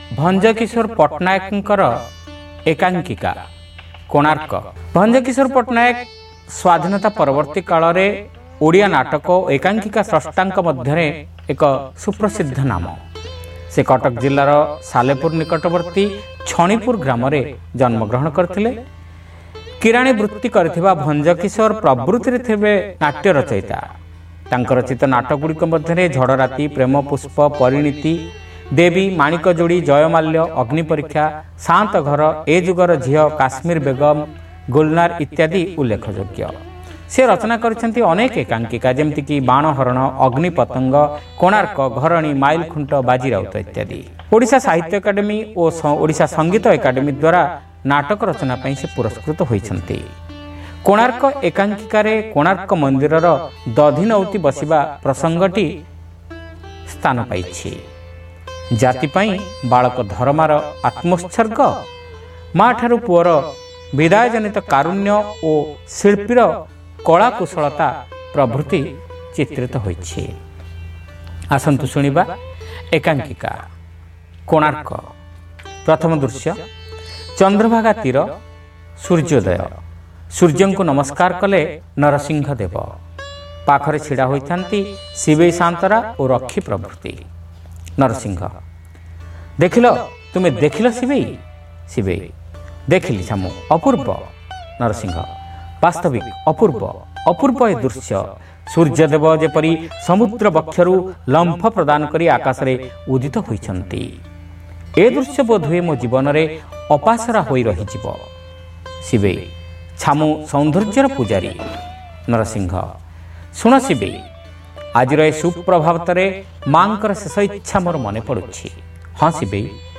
Audio One act Play : Konark (Part-1)